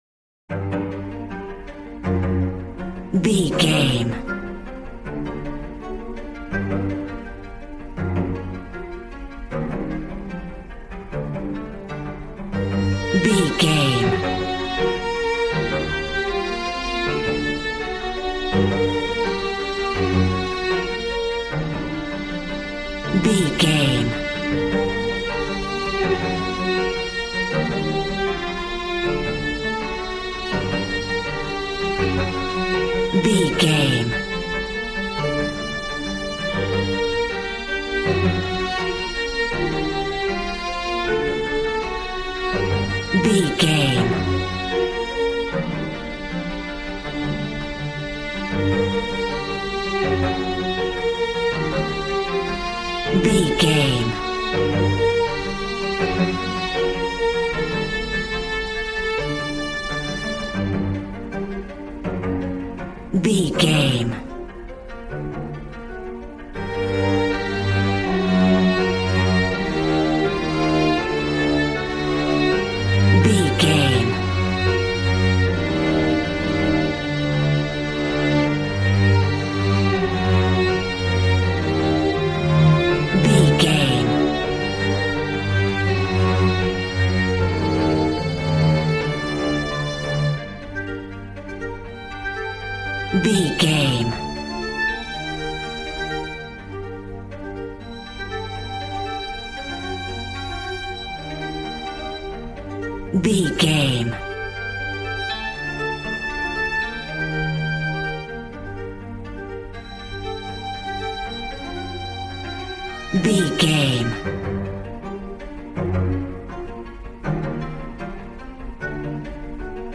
Aeolian/Minor
regal
brass